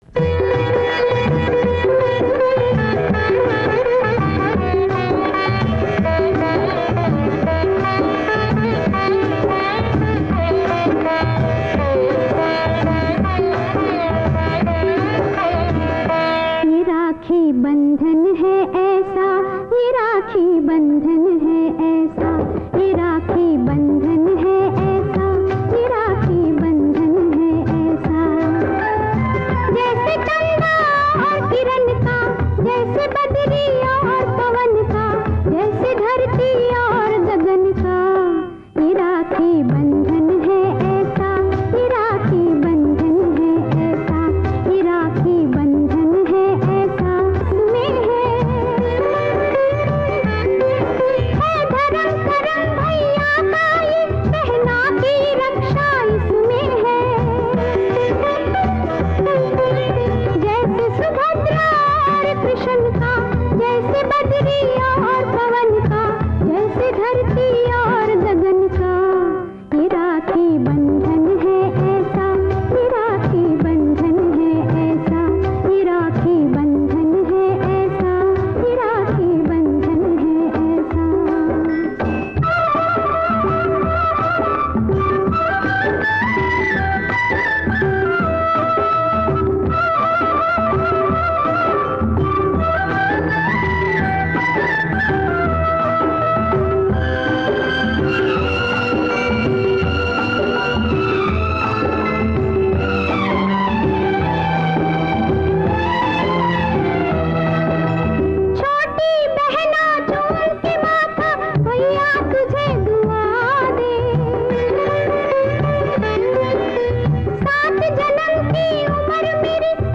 mellifluous voice